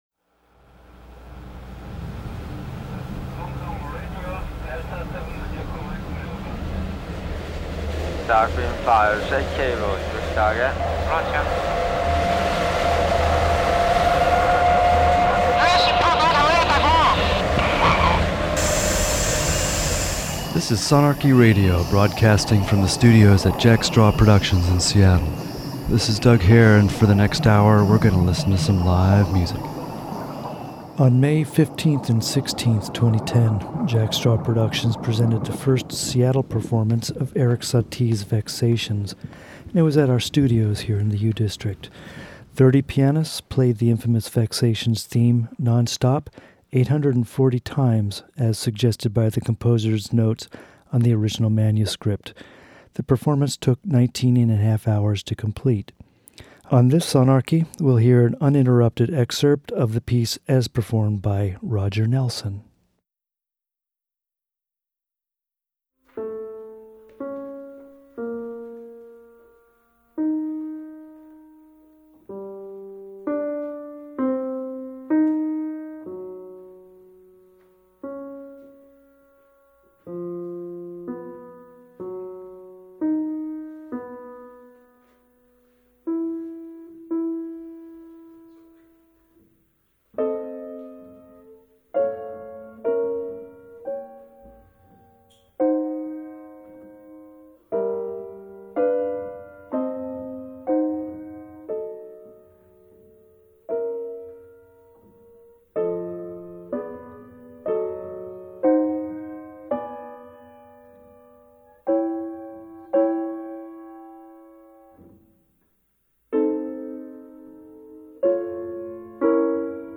Fourteen improvisors are called to the studio
to create a huge, haywire, and engaging performance.